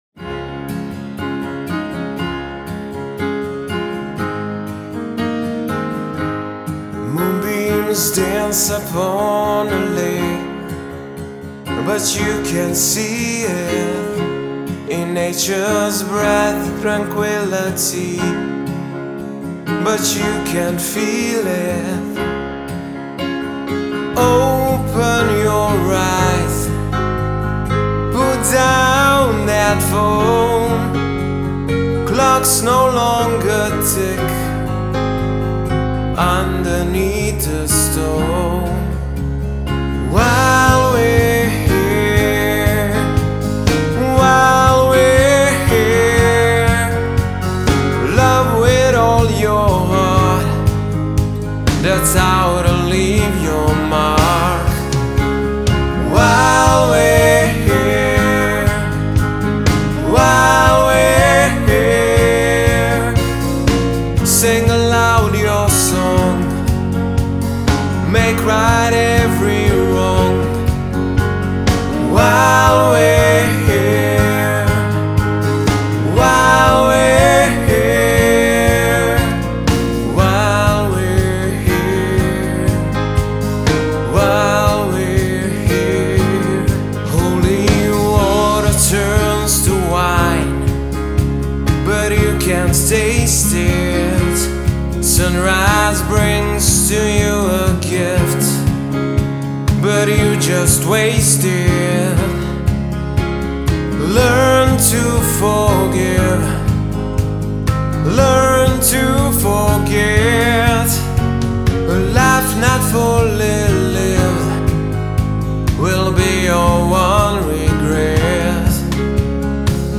• I like the vocals and how smooth they sound.
• I love that the vocals were so soft and professional.
• The piano and guitar were very relaxing.
Love the harmony in the singing.